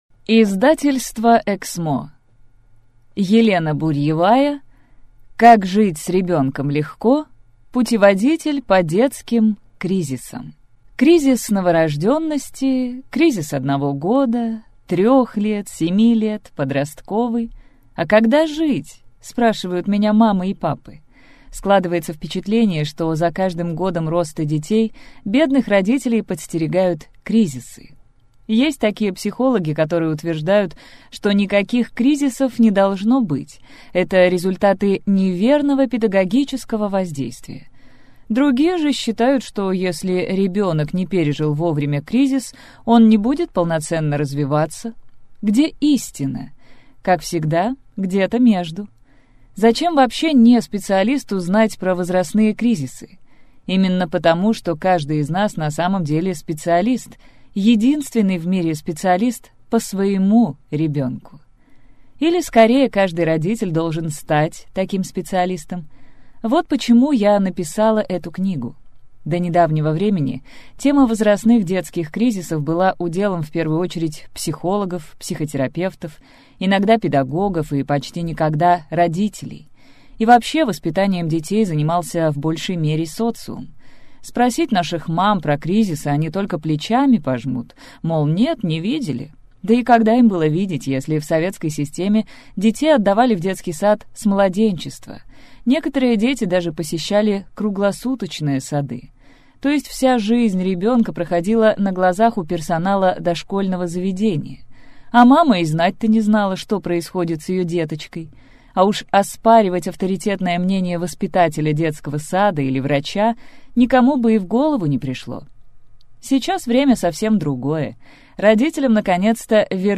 Аудиокнига Как жить с ребёнком легко. Путеводитель по детским кризисам | Библиотека аудиокниг